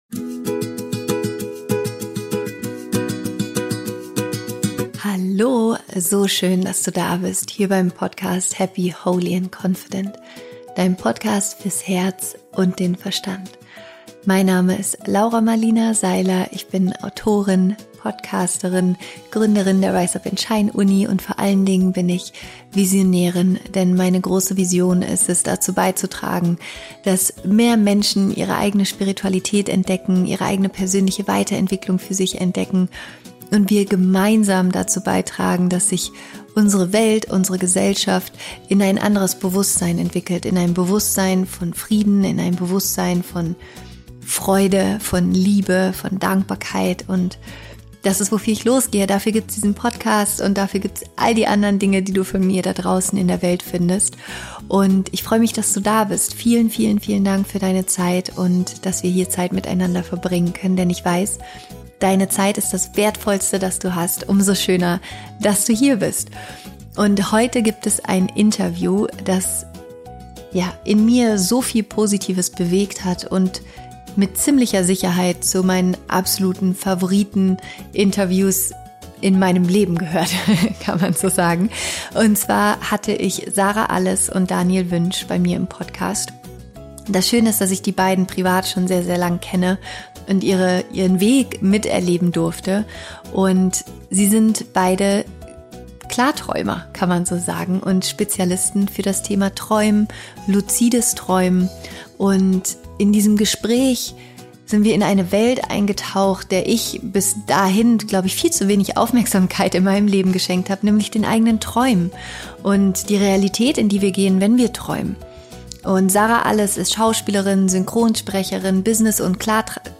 Es ist ein so faszinierendes Interview und ich bin mir sicher, dass du danach am liebsten sofort schlafen und träumen gehen möchtest.